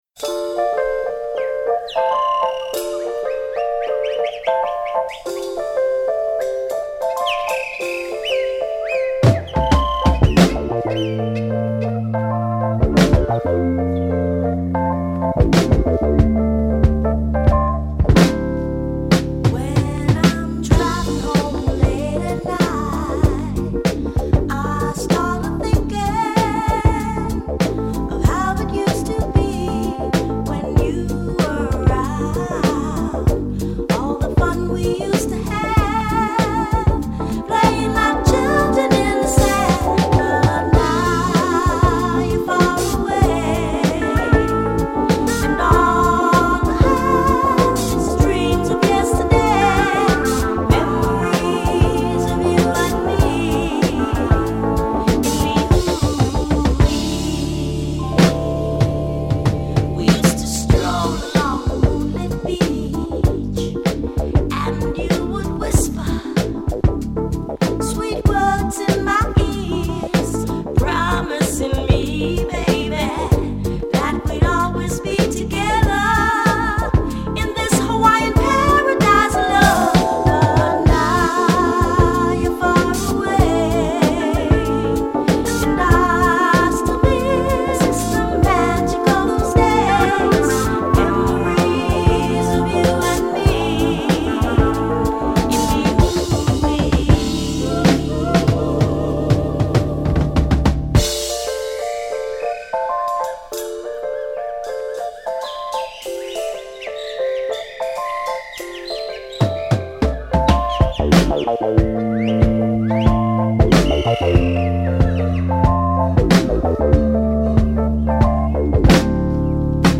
＊試聴はダイジェストです。